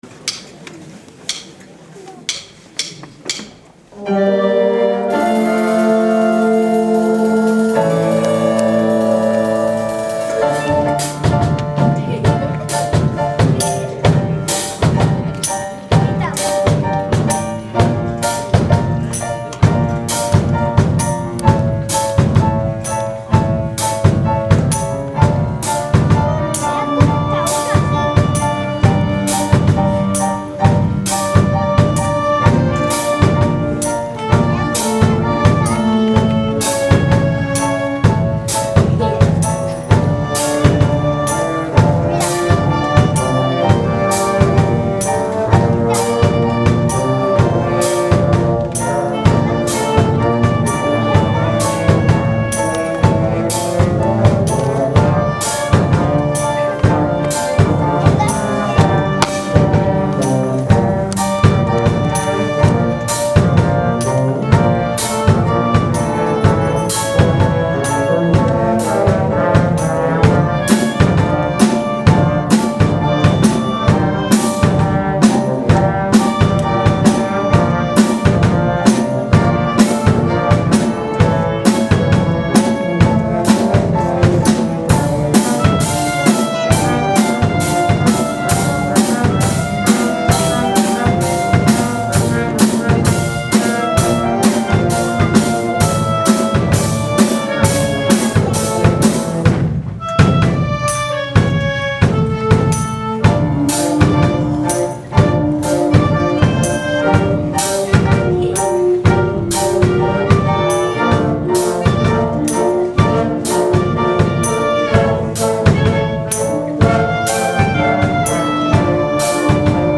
La Agrupación Musical de Totana celebró el pasado viernes 25 de noviembre un concierto en el teatro del Centro Sociocultural "La Cárcel" en honor a la festividad de Santa Cecilia, patrona de los músicos.